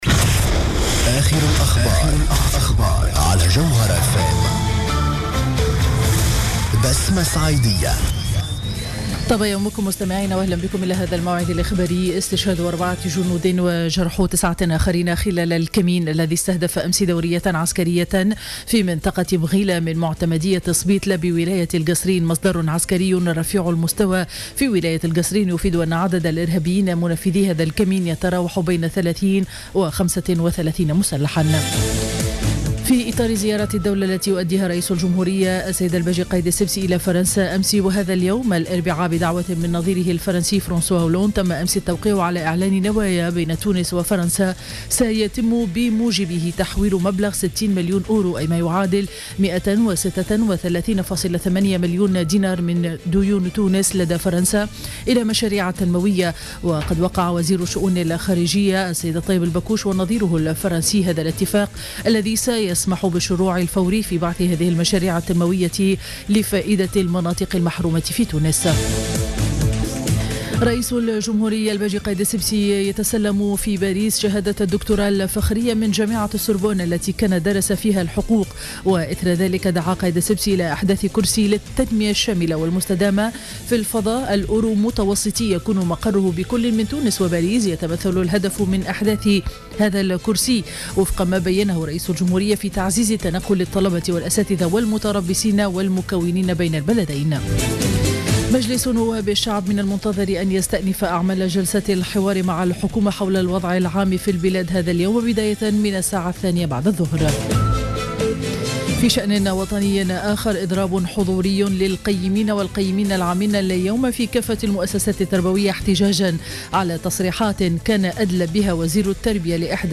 نشرة أخبار السابعة صباحا ليوم الإربعاء 8 أفريل 2015